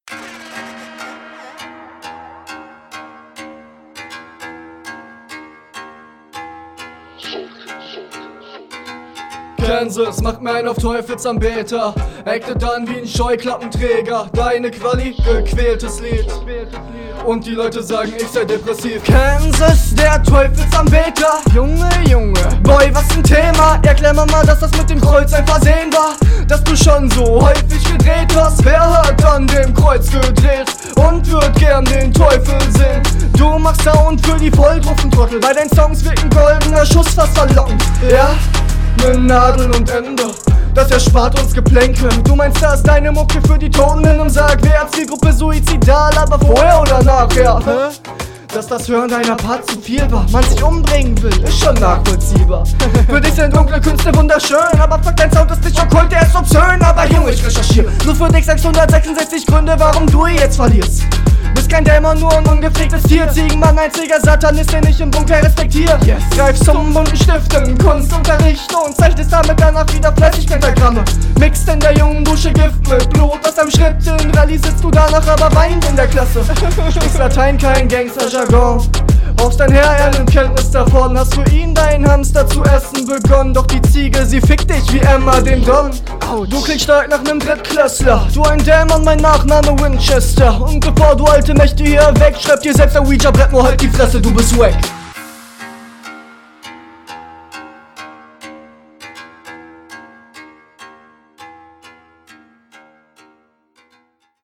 Höchst interessanter Beat hier wieder.
Beat fühl ich direkt mehr als in Runde 1.